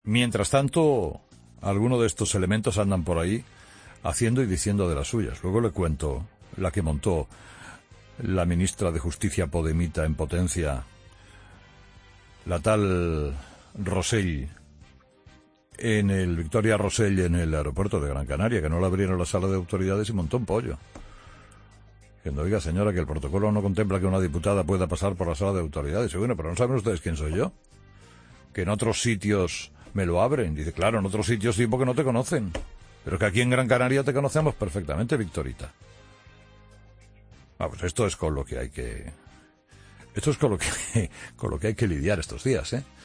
Vamos, esto es con lo que hay que lidiar estos días”, sentenció Herrera entre risas.